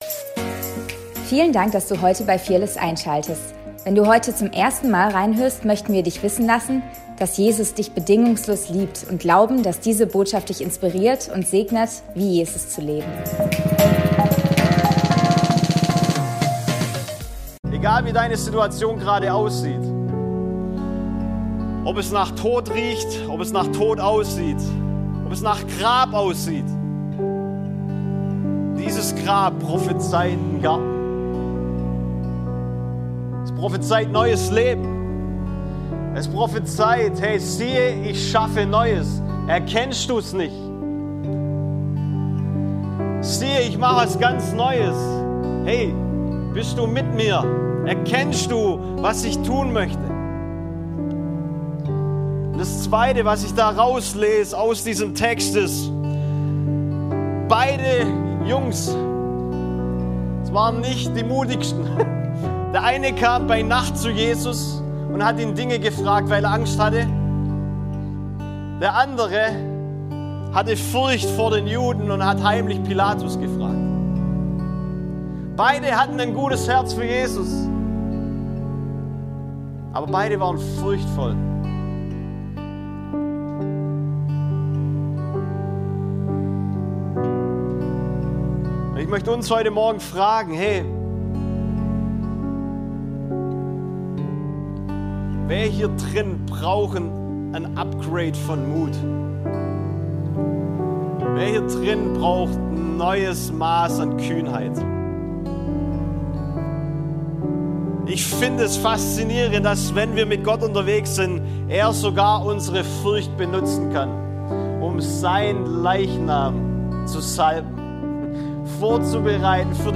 Predigt vom 18.05.2025